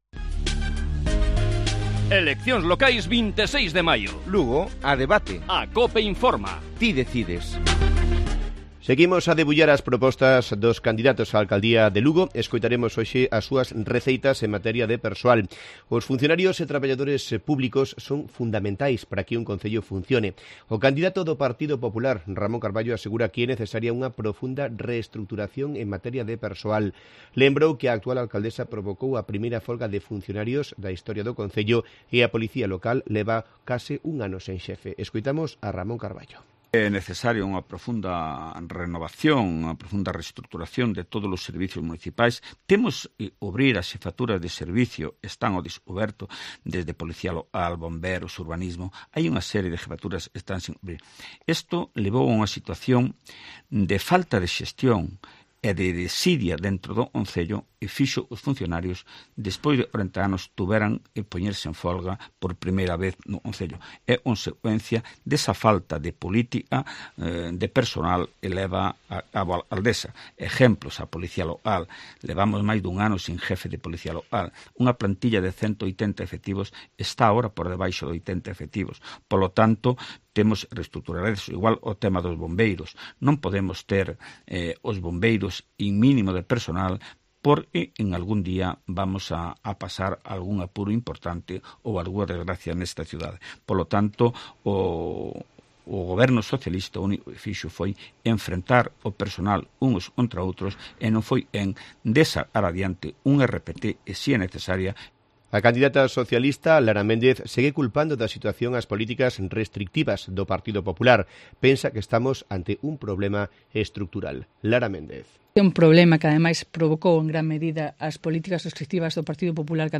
Lugo a debate: Los candidatos hablan sobre sus propuestas en materia de personal